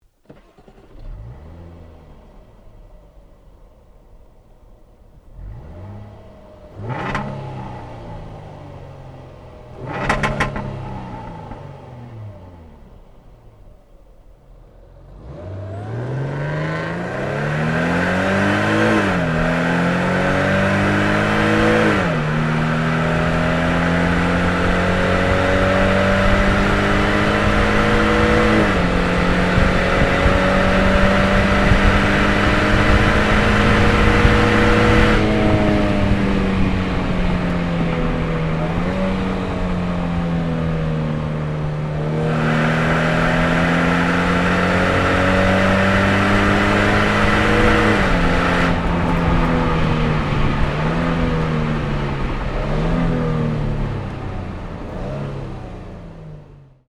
صدای اگزوز